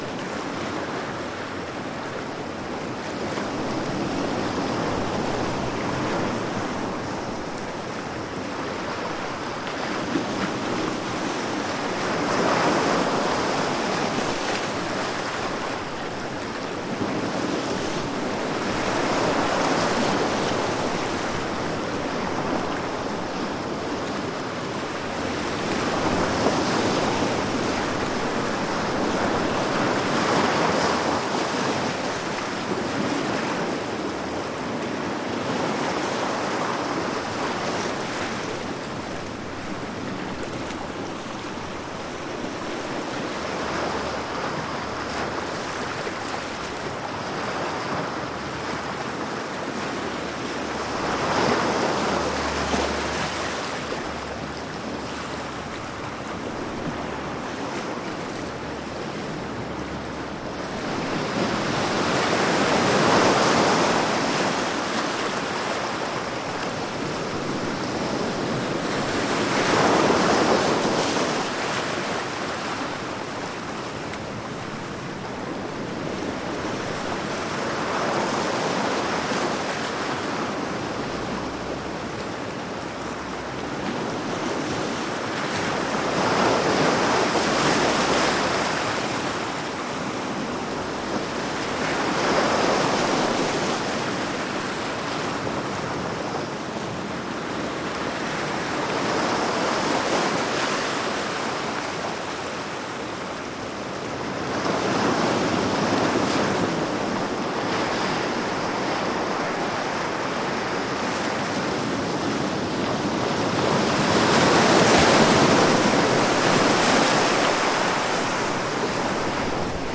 Звуки океанских волн
Шум средних волн океана
shum-srednikh-voln-okeana.mp3